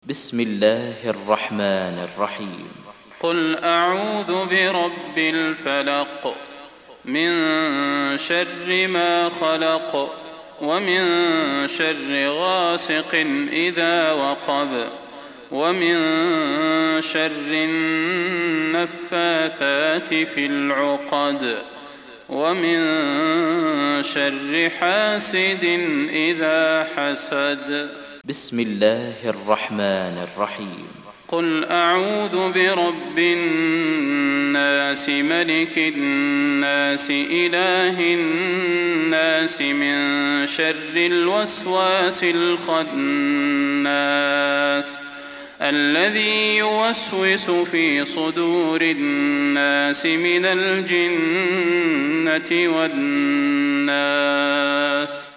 فروض مغرب البدير 1420